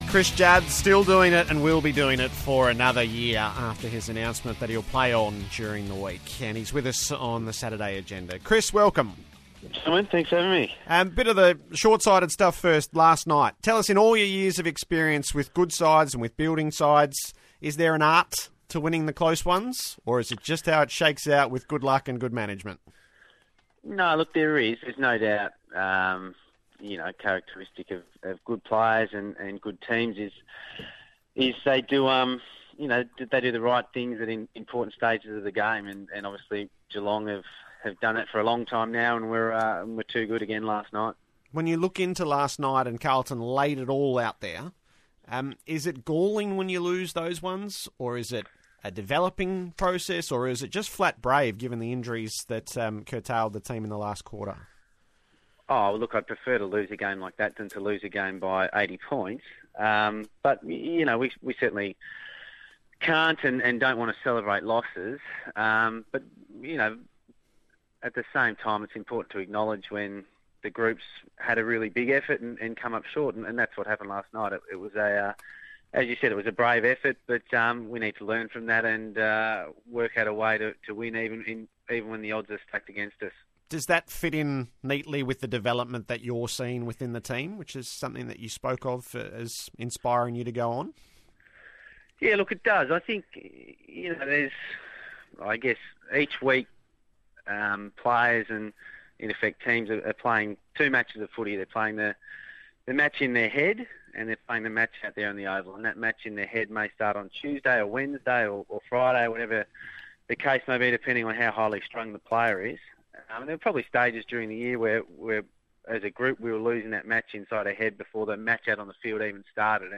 Blues champ Chris Judd spoke to ABC Grandstand after Carlton's Round 21 loss against the Cats.